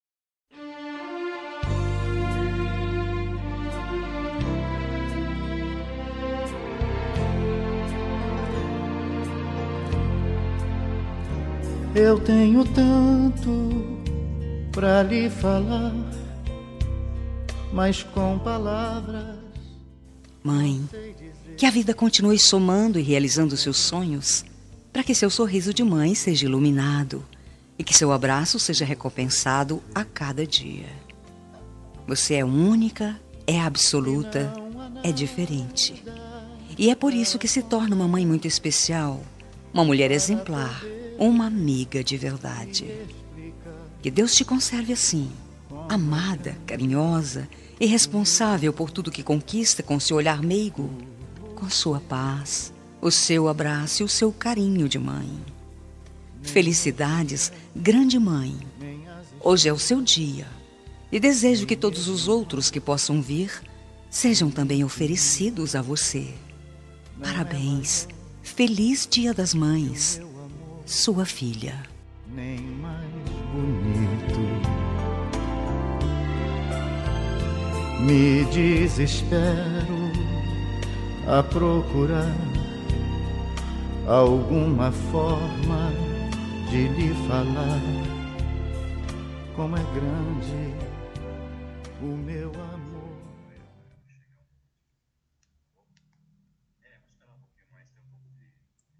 Dia das Mães – Para minha Mãe – Voz Feminina – Cód: 6500